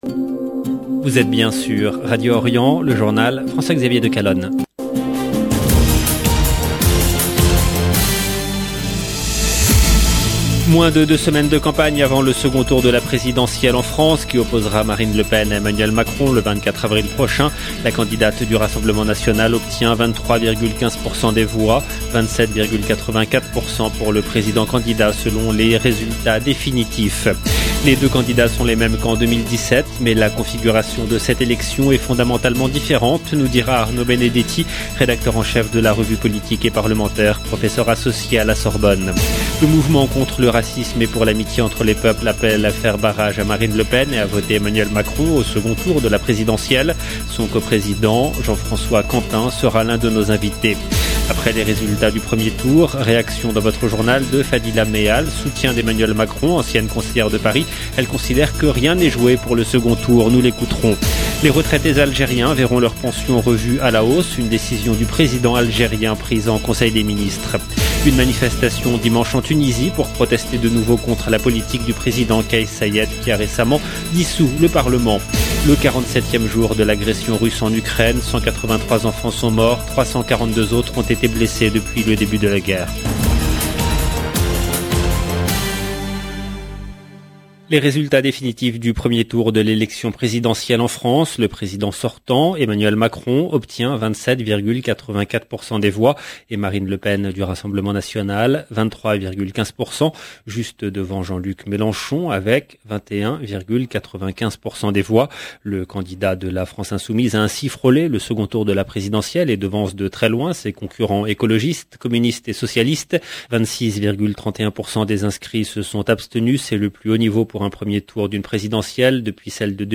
LE JOURNAL DU SOIR EN LANGUE FRANCAISE DU 11/04/22